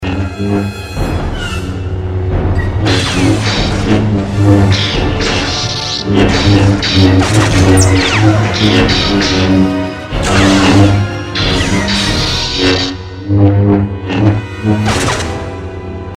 На этой странице собраны реалистичные звуки светового меча из вселенной Star Wars.
Бой световых мечей